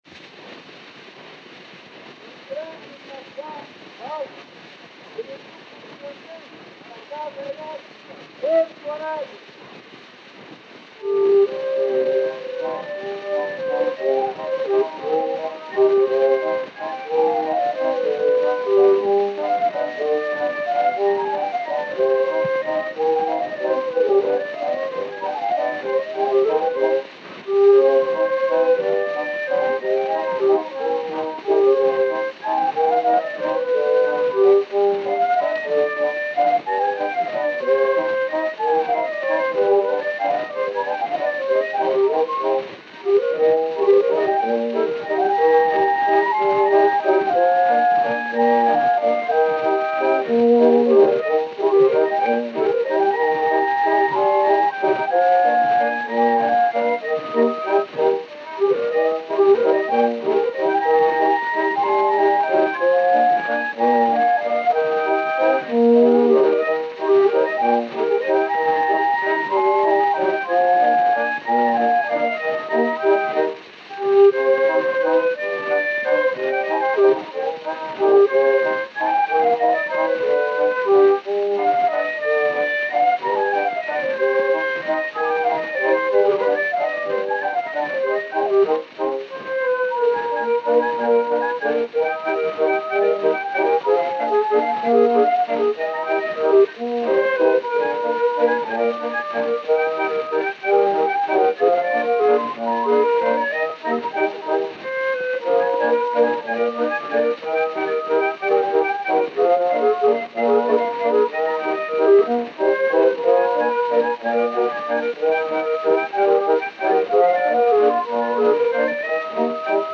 O gênero musical é "Valsa".